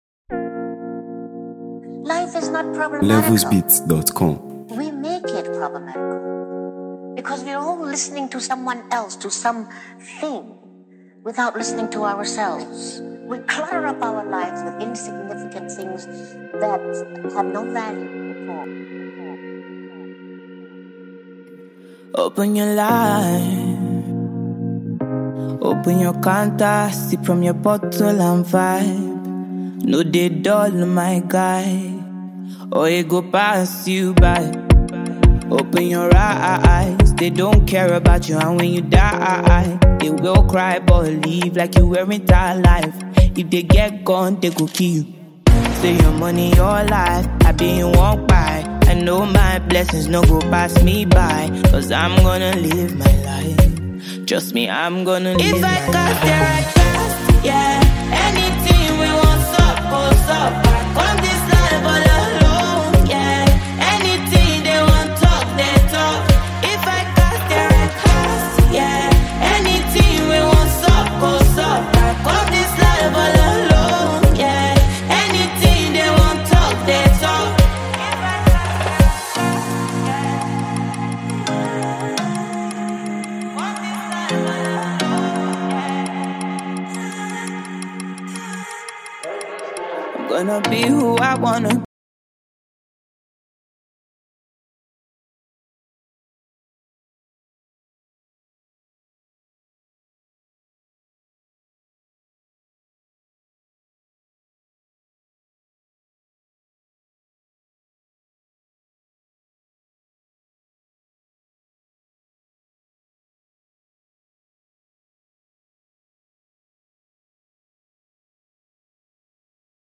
Afropop
Known for her striking vocals